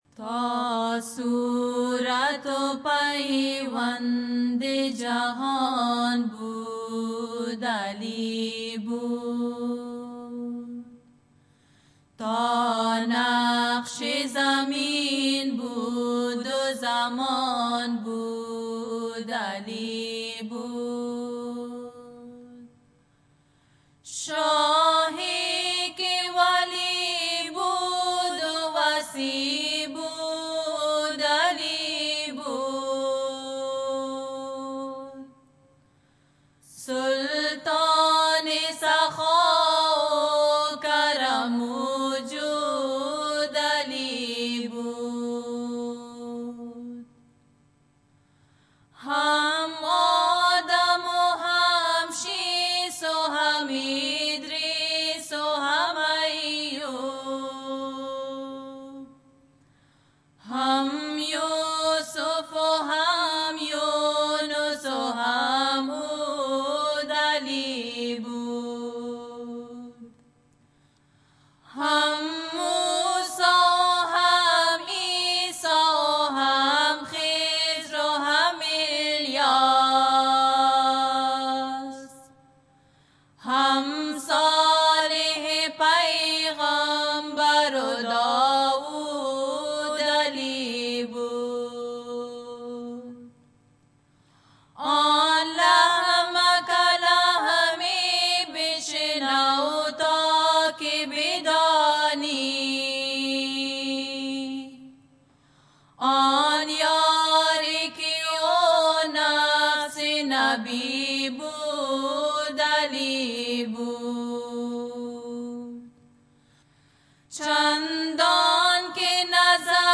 Qasida: Ta Surato Paiwande Jahan Bud Ali Bud – Ali the ever present